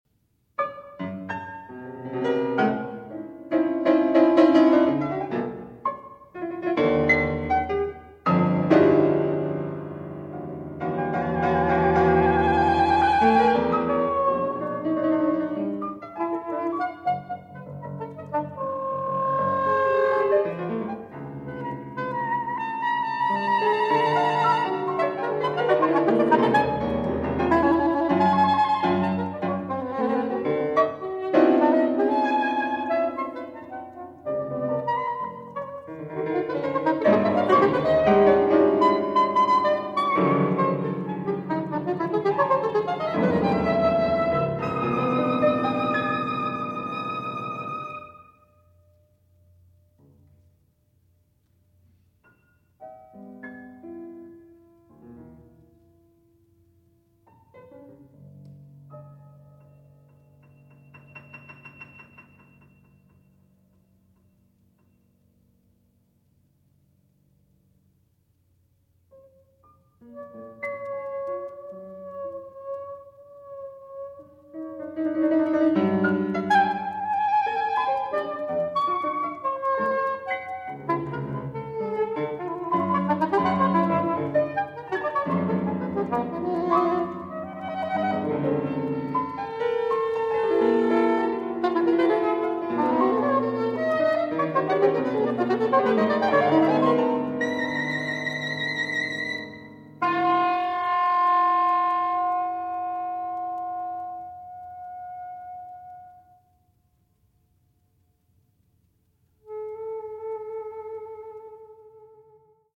Instrumentation: soprano saxophone, piano